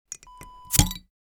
Beer bottle open sound effect .wav #1
Description: The sound of opening a beer bottle with a bottle opener
Properties: 48.000 kHz 16-bit Stereo
A beep sound is embedded in the audio preview file but it is not present in the high resolution downloadable wav file.
beer-bottle-open-preview-1.mp3